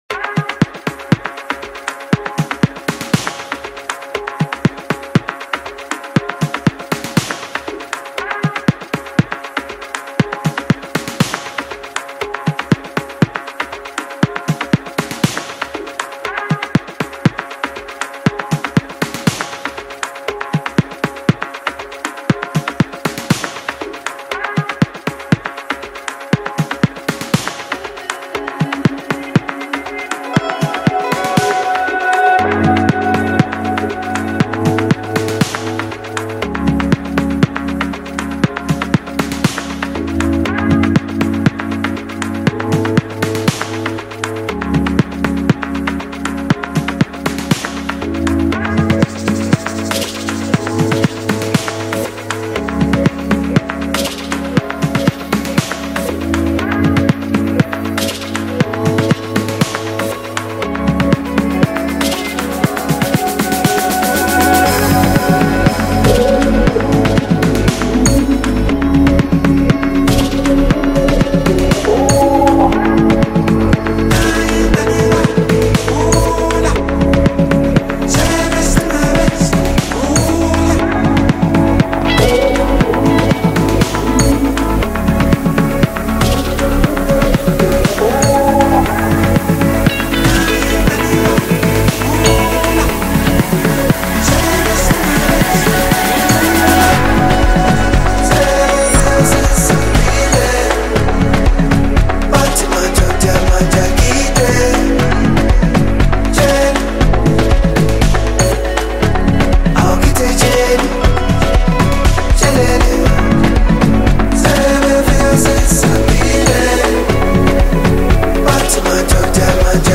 Home » Amapiano » DJ Mix » Gospel